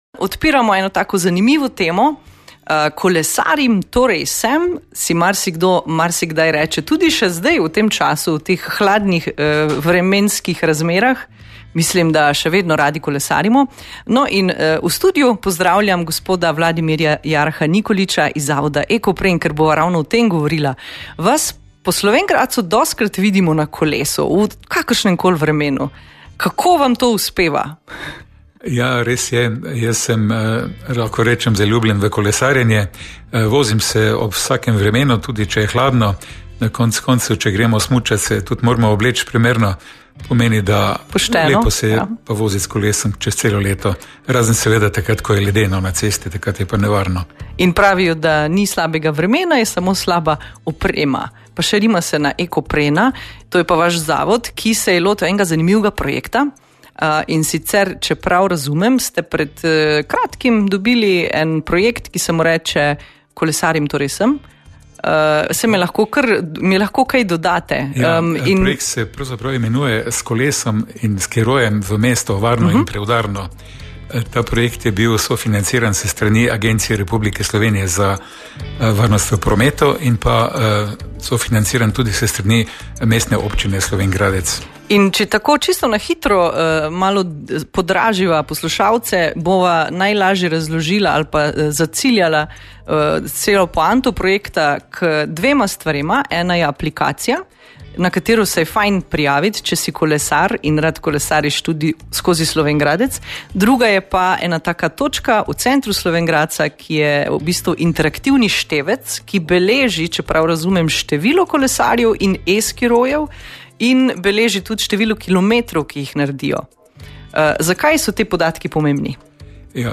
O tem, kako se vključite v projekt tudi vi smo se pogovarjali